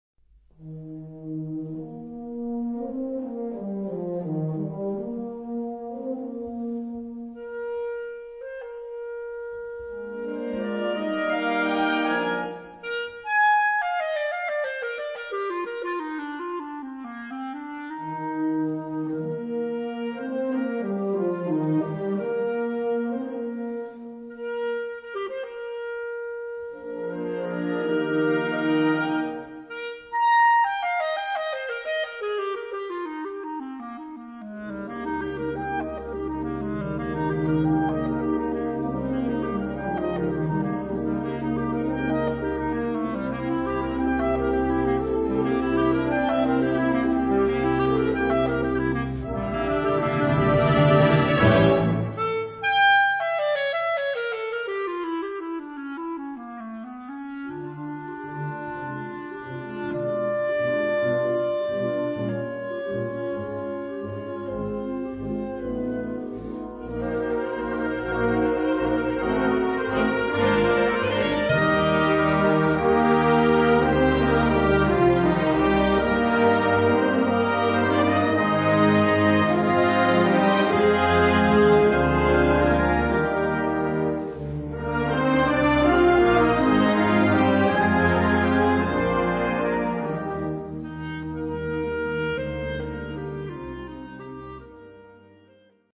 Gattung: Solo für Klarinette und Blasorchester
Besetzung: Blasorchester